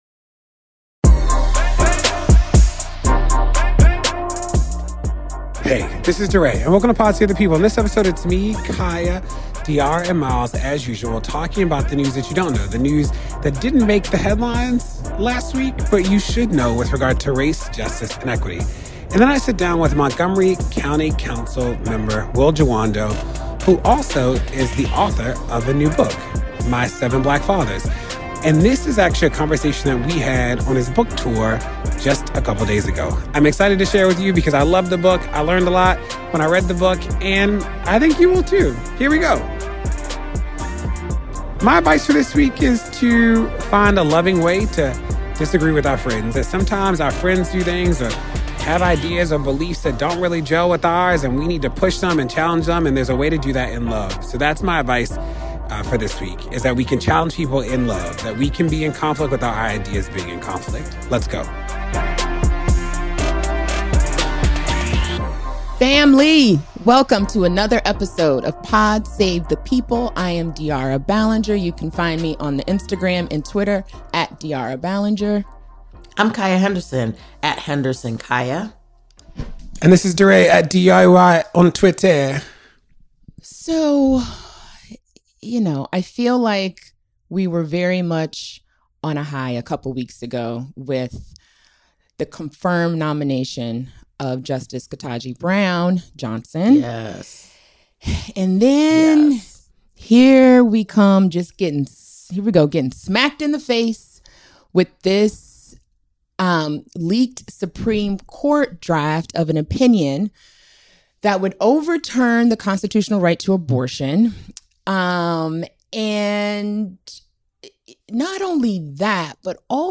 DeRay interviews Montgomery County Council member and author Will Jawando about his new book My Seven Black Fathers: A Young Activist's Memoir of Race, Family, and the Mentors Who Made Him Whole.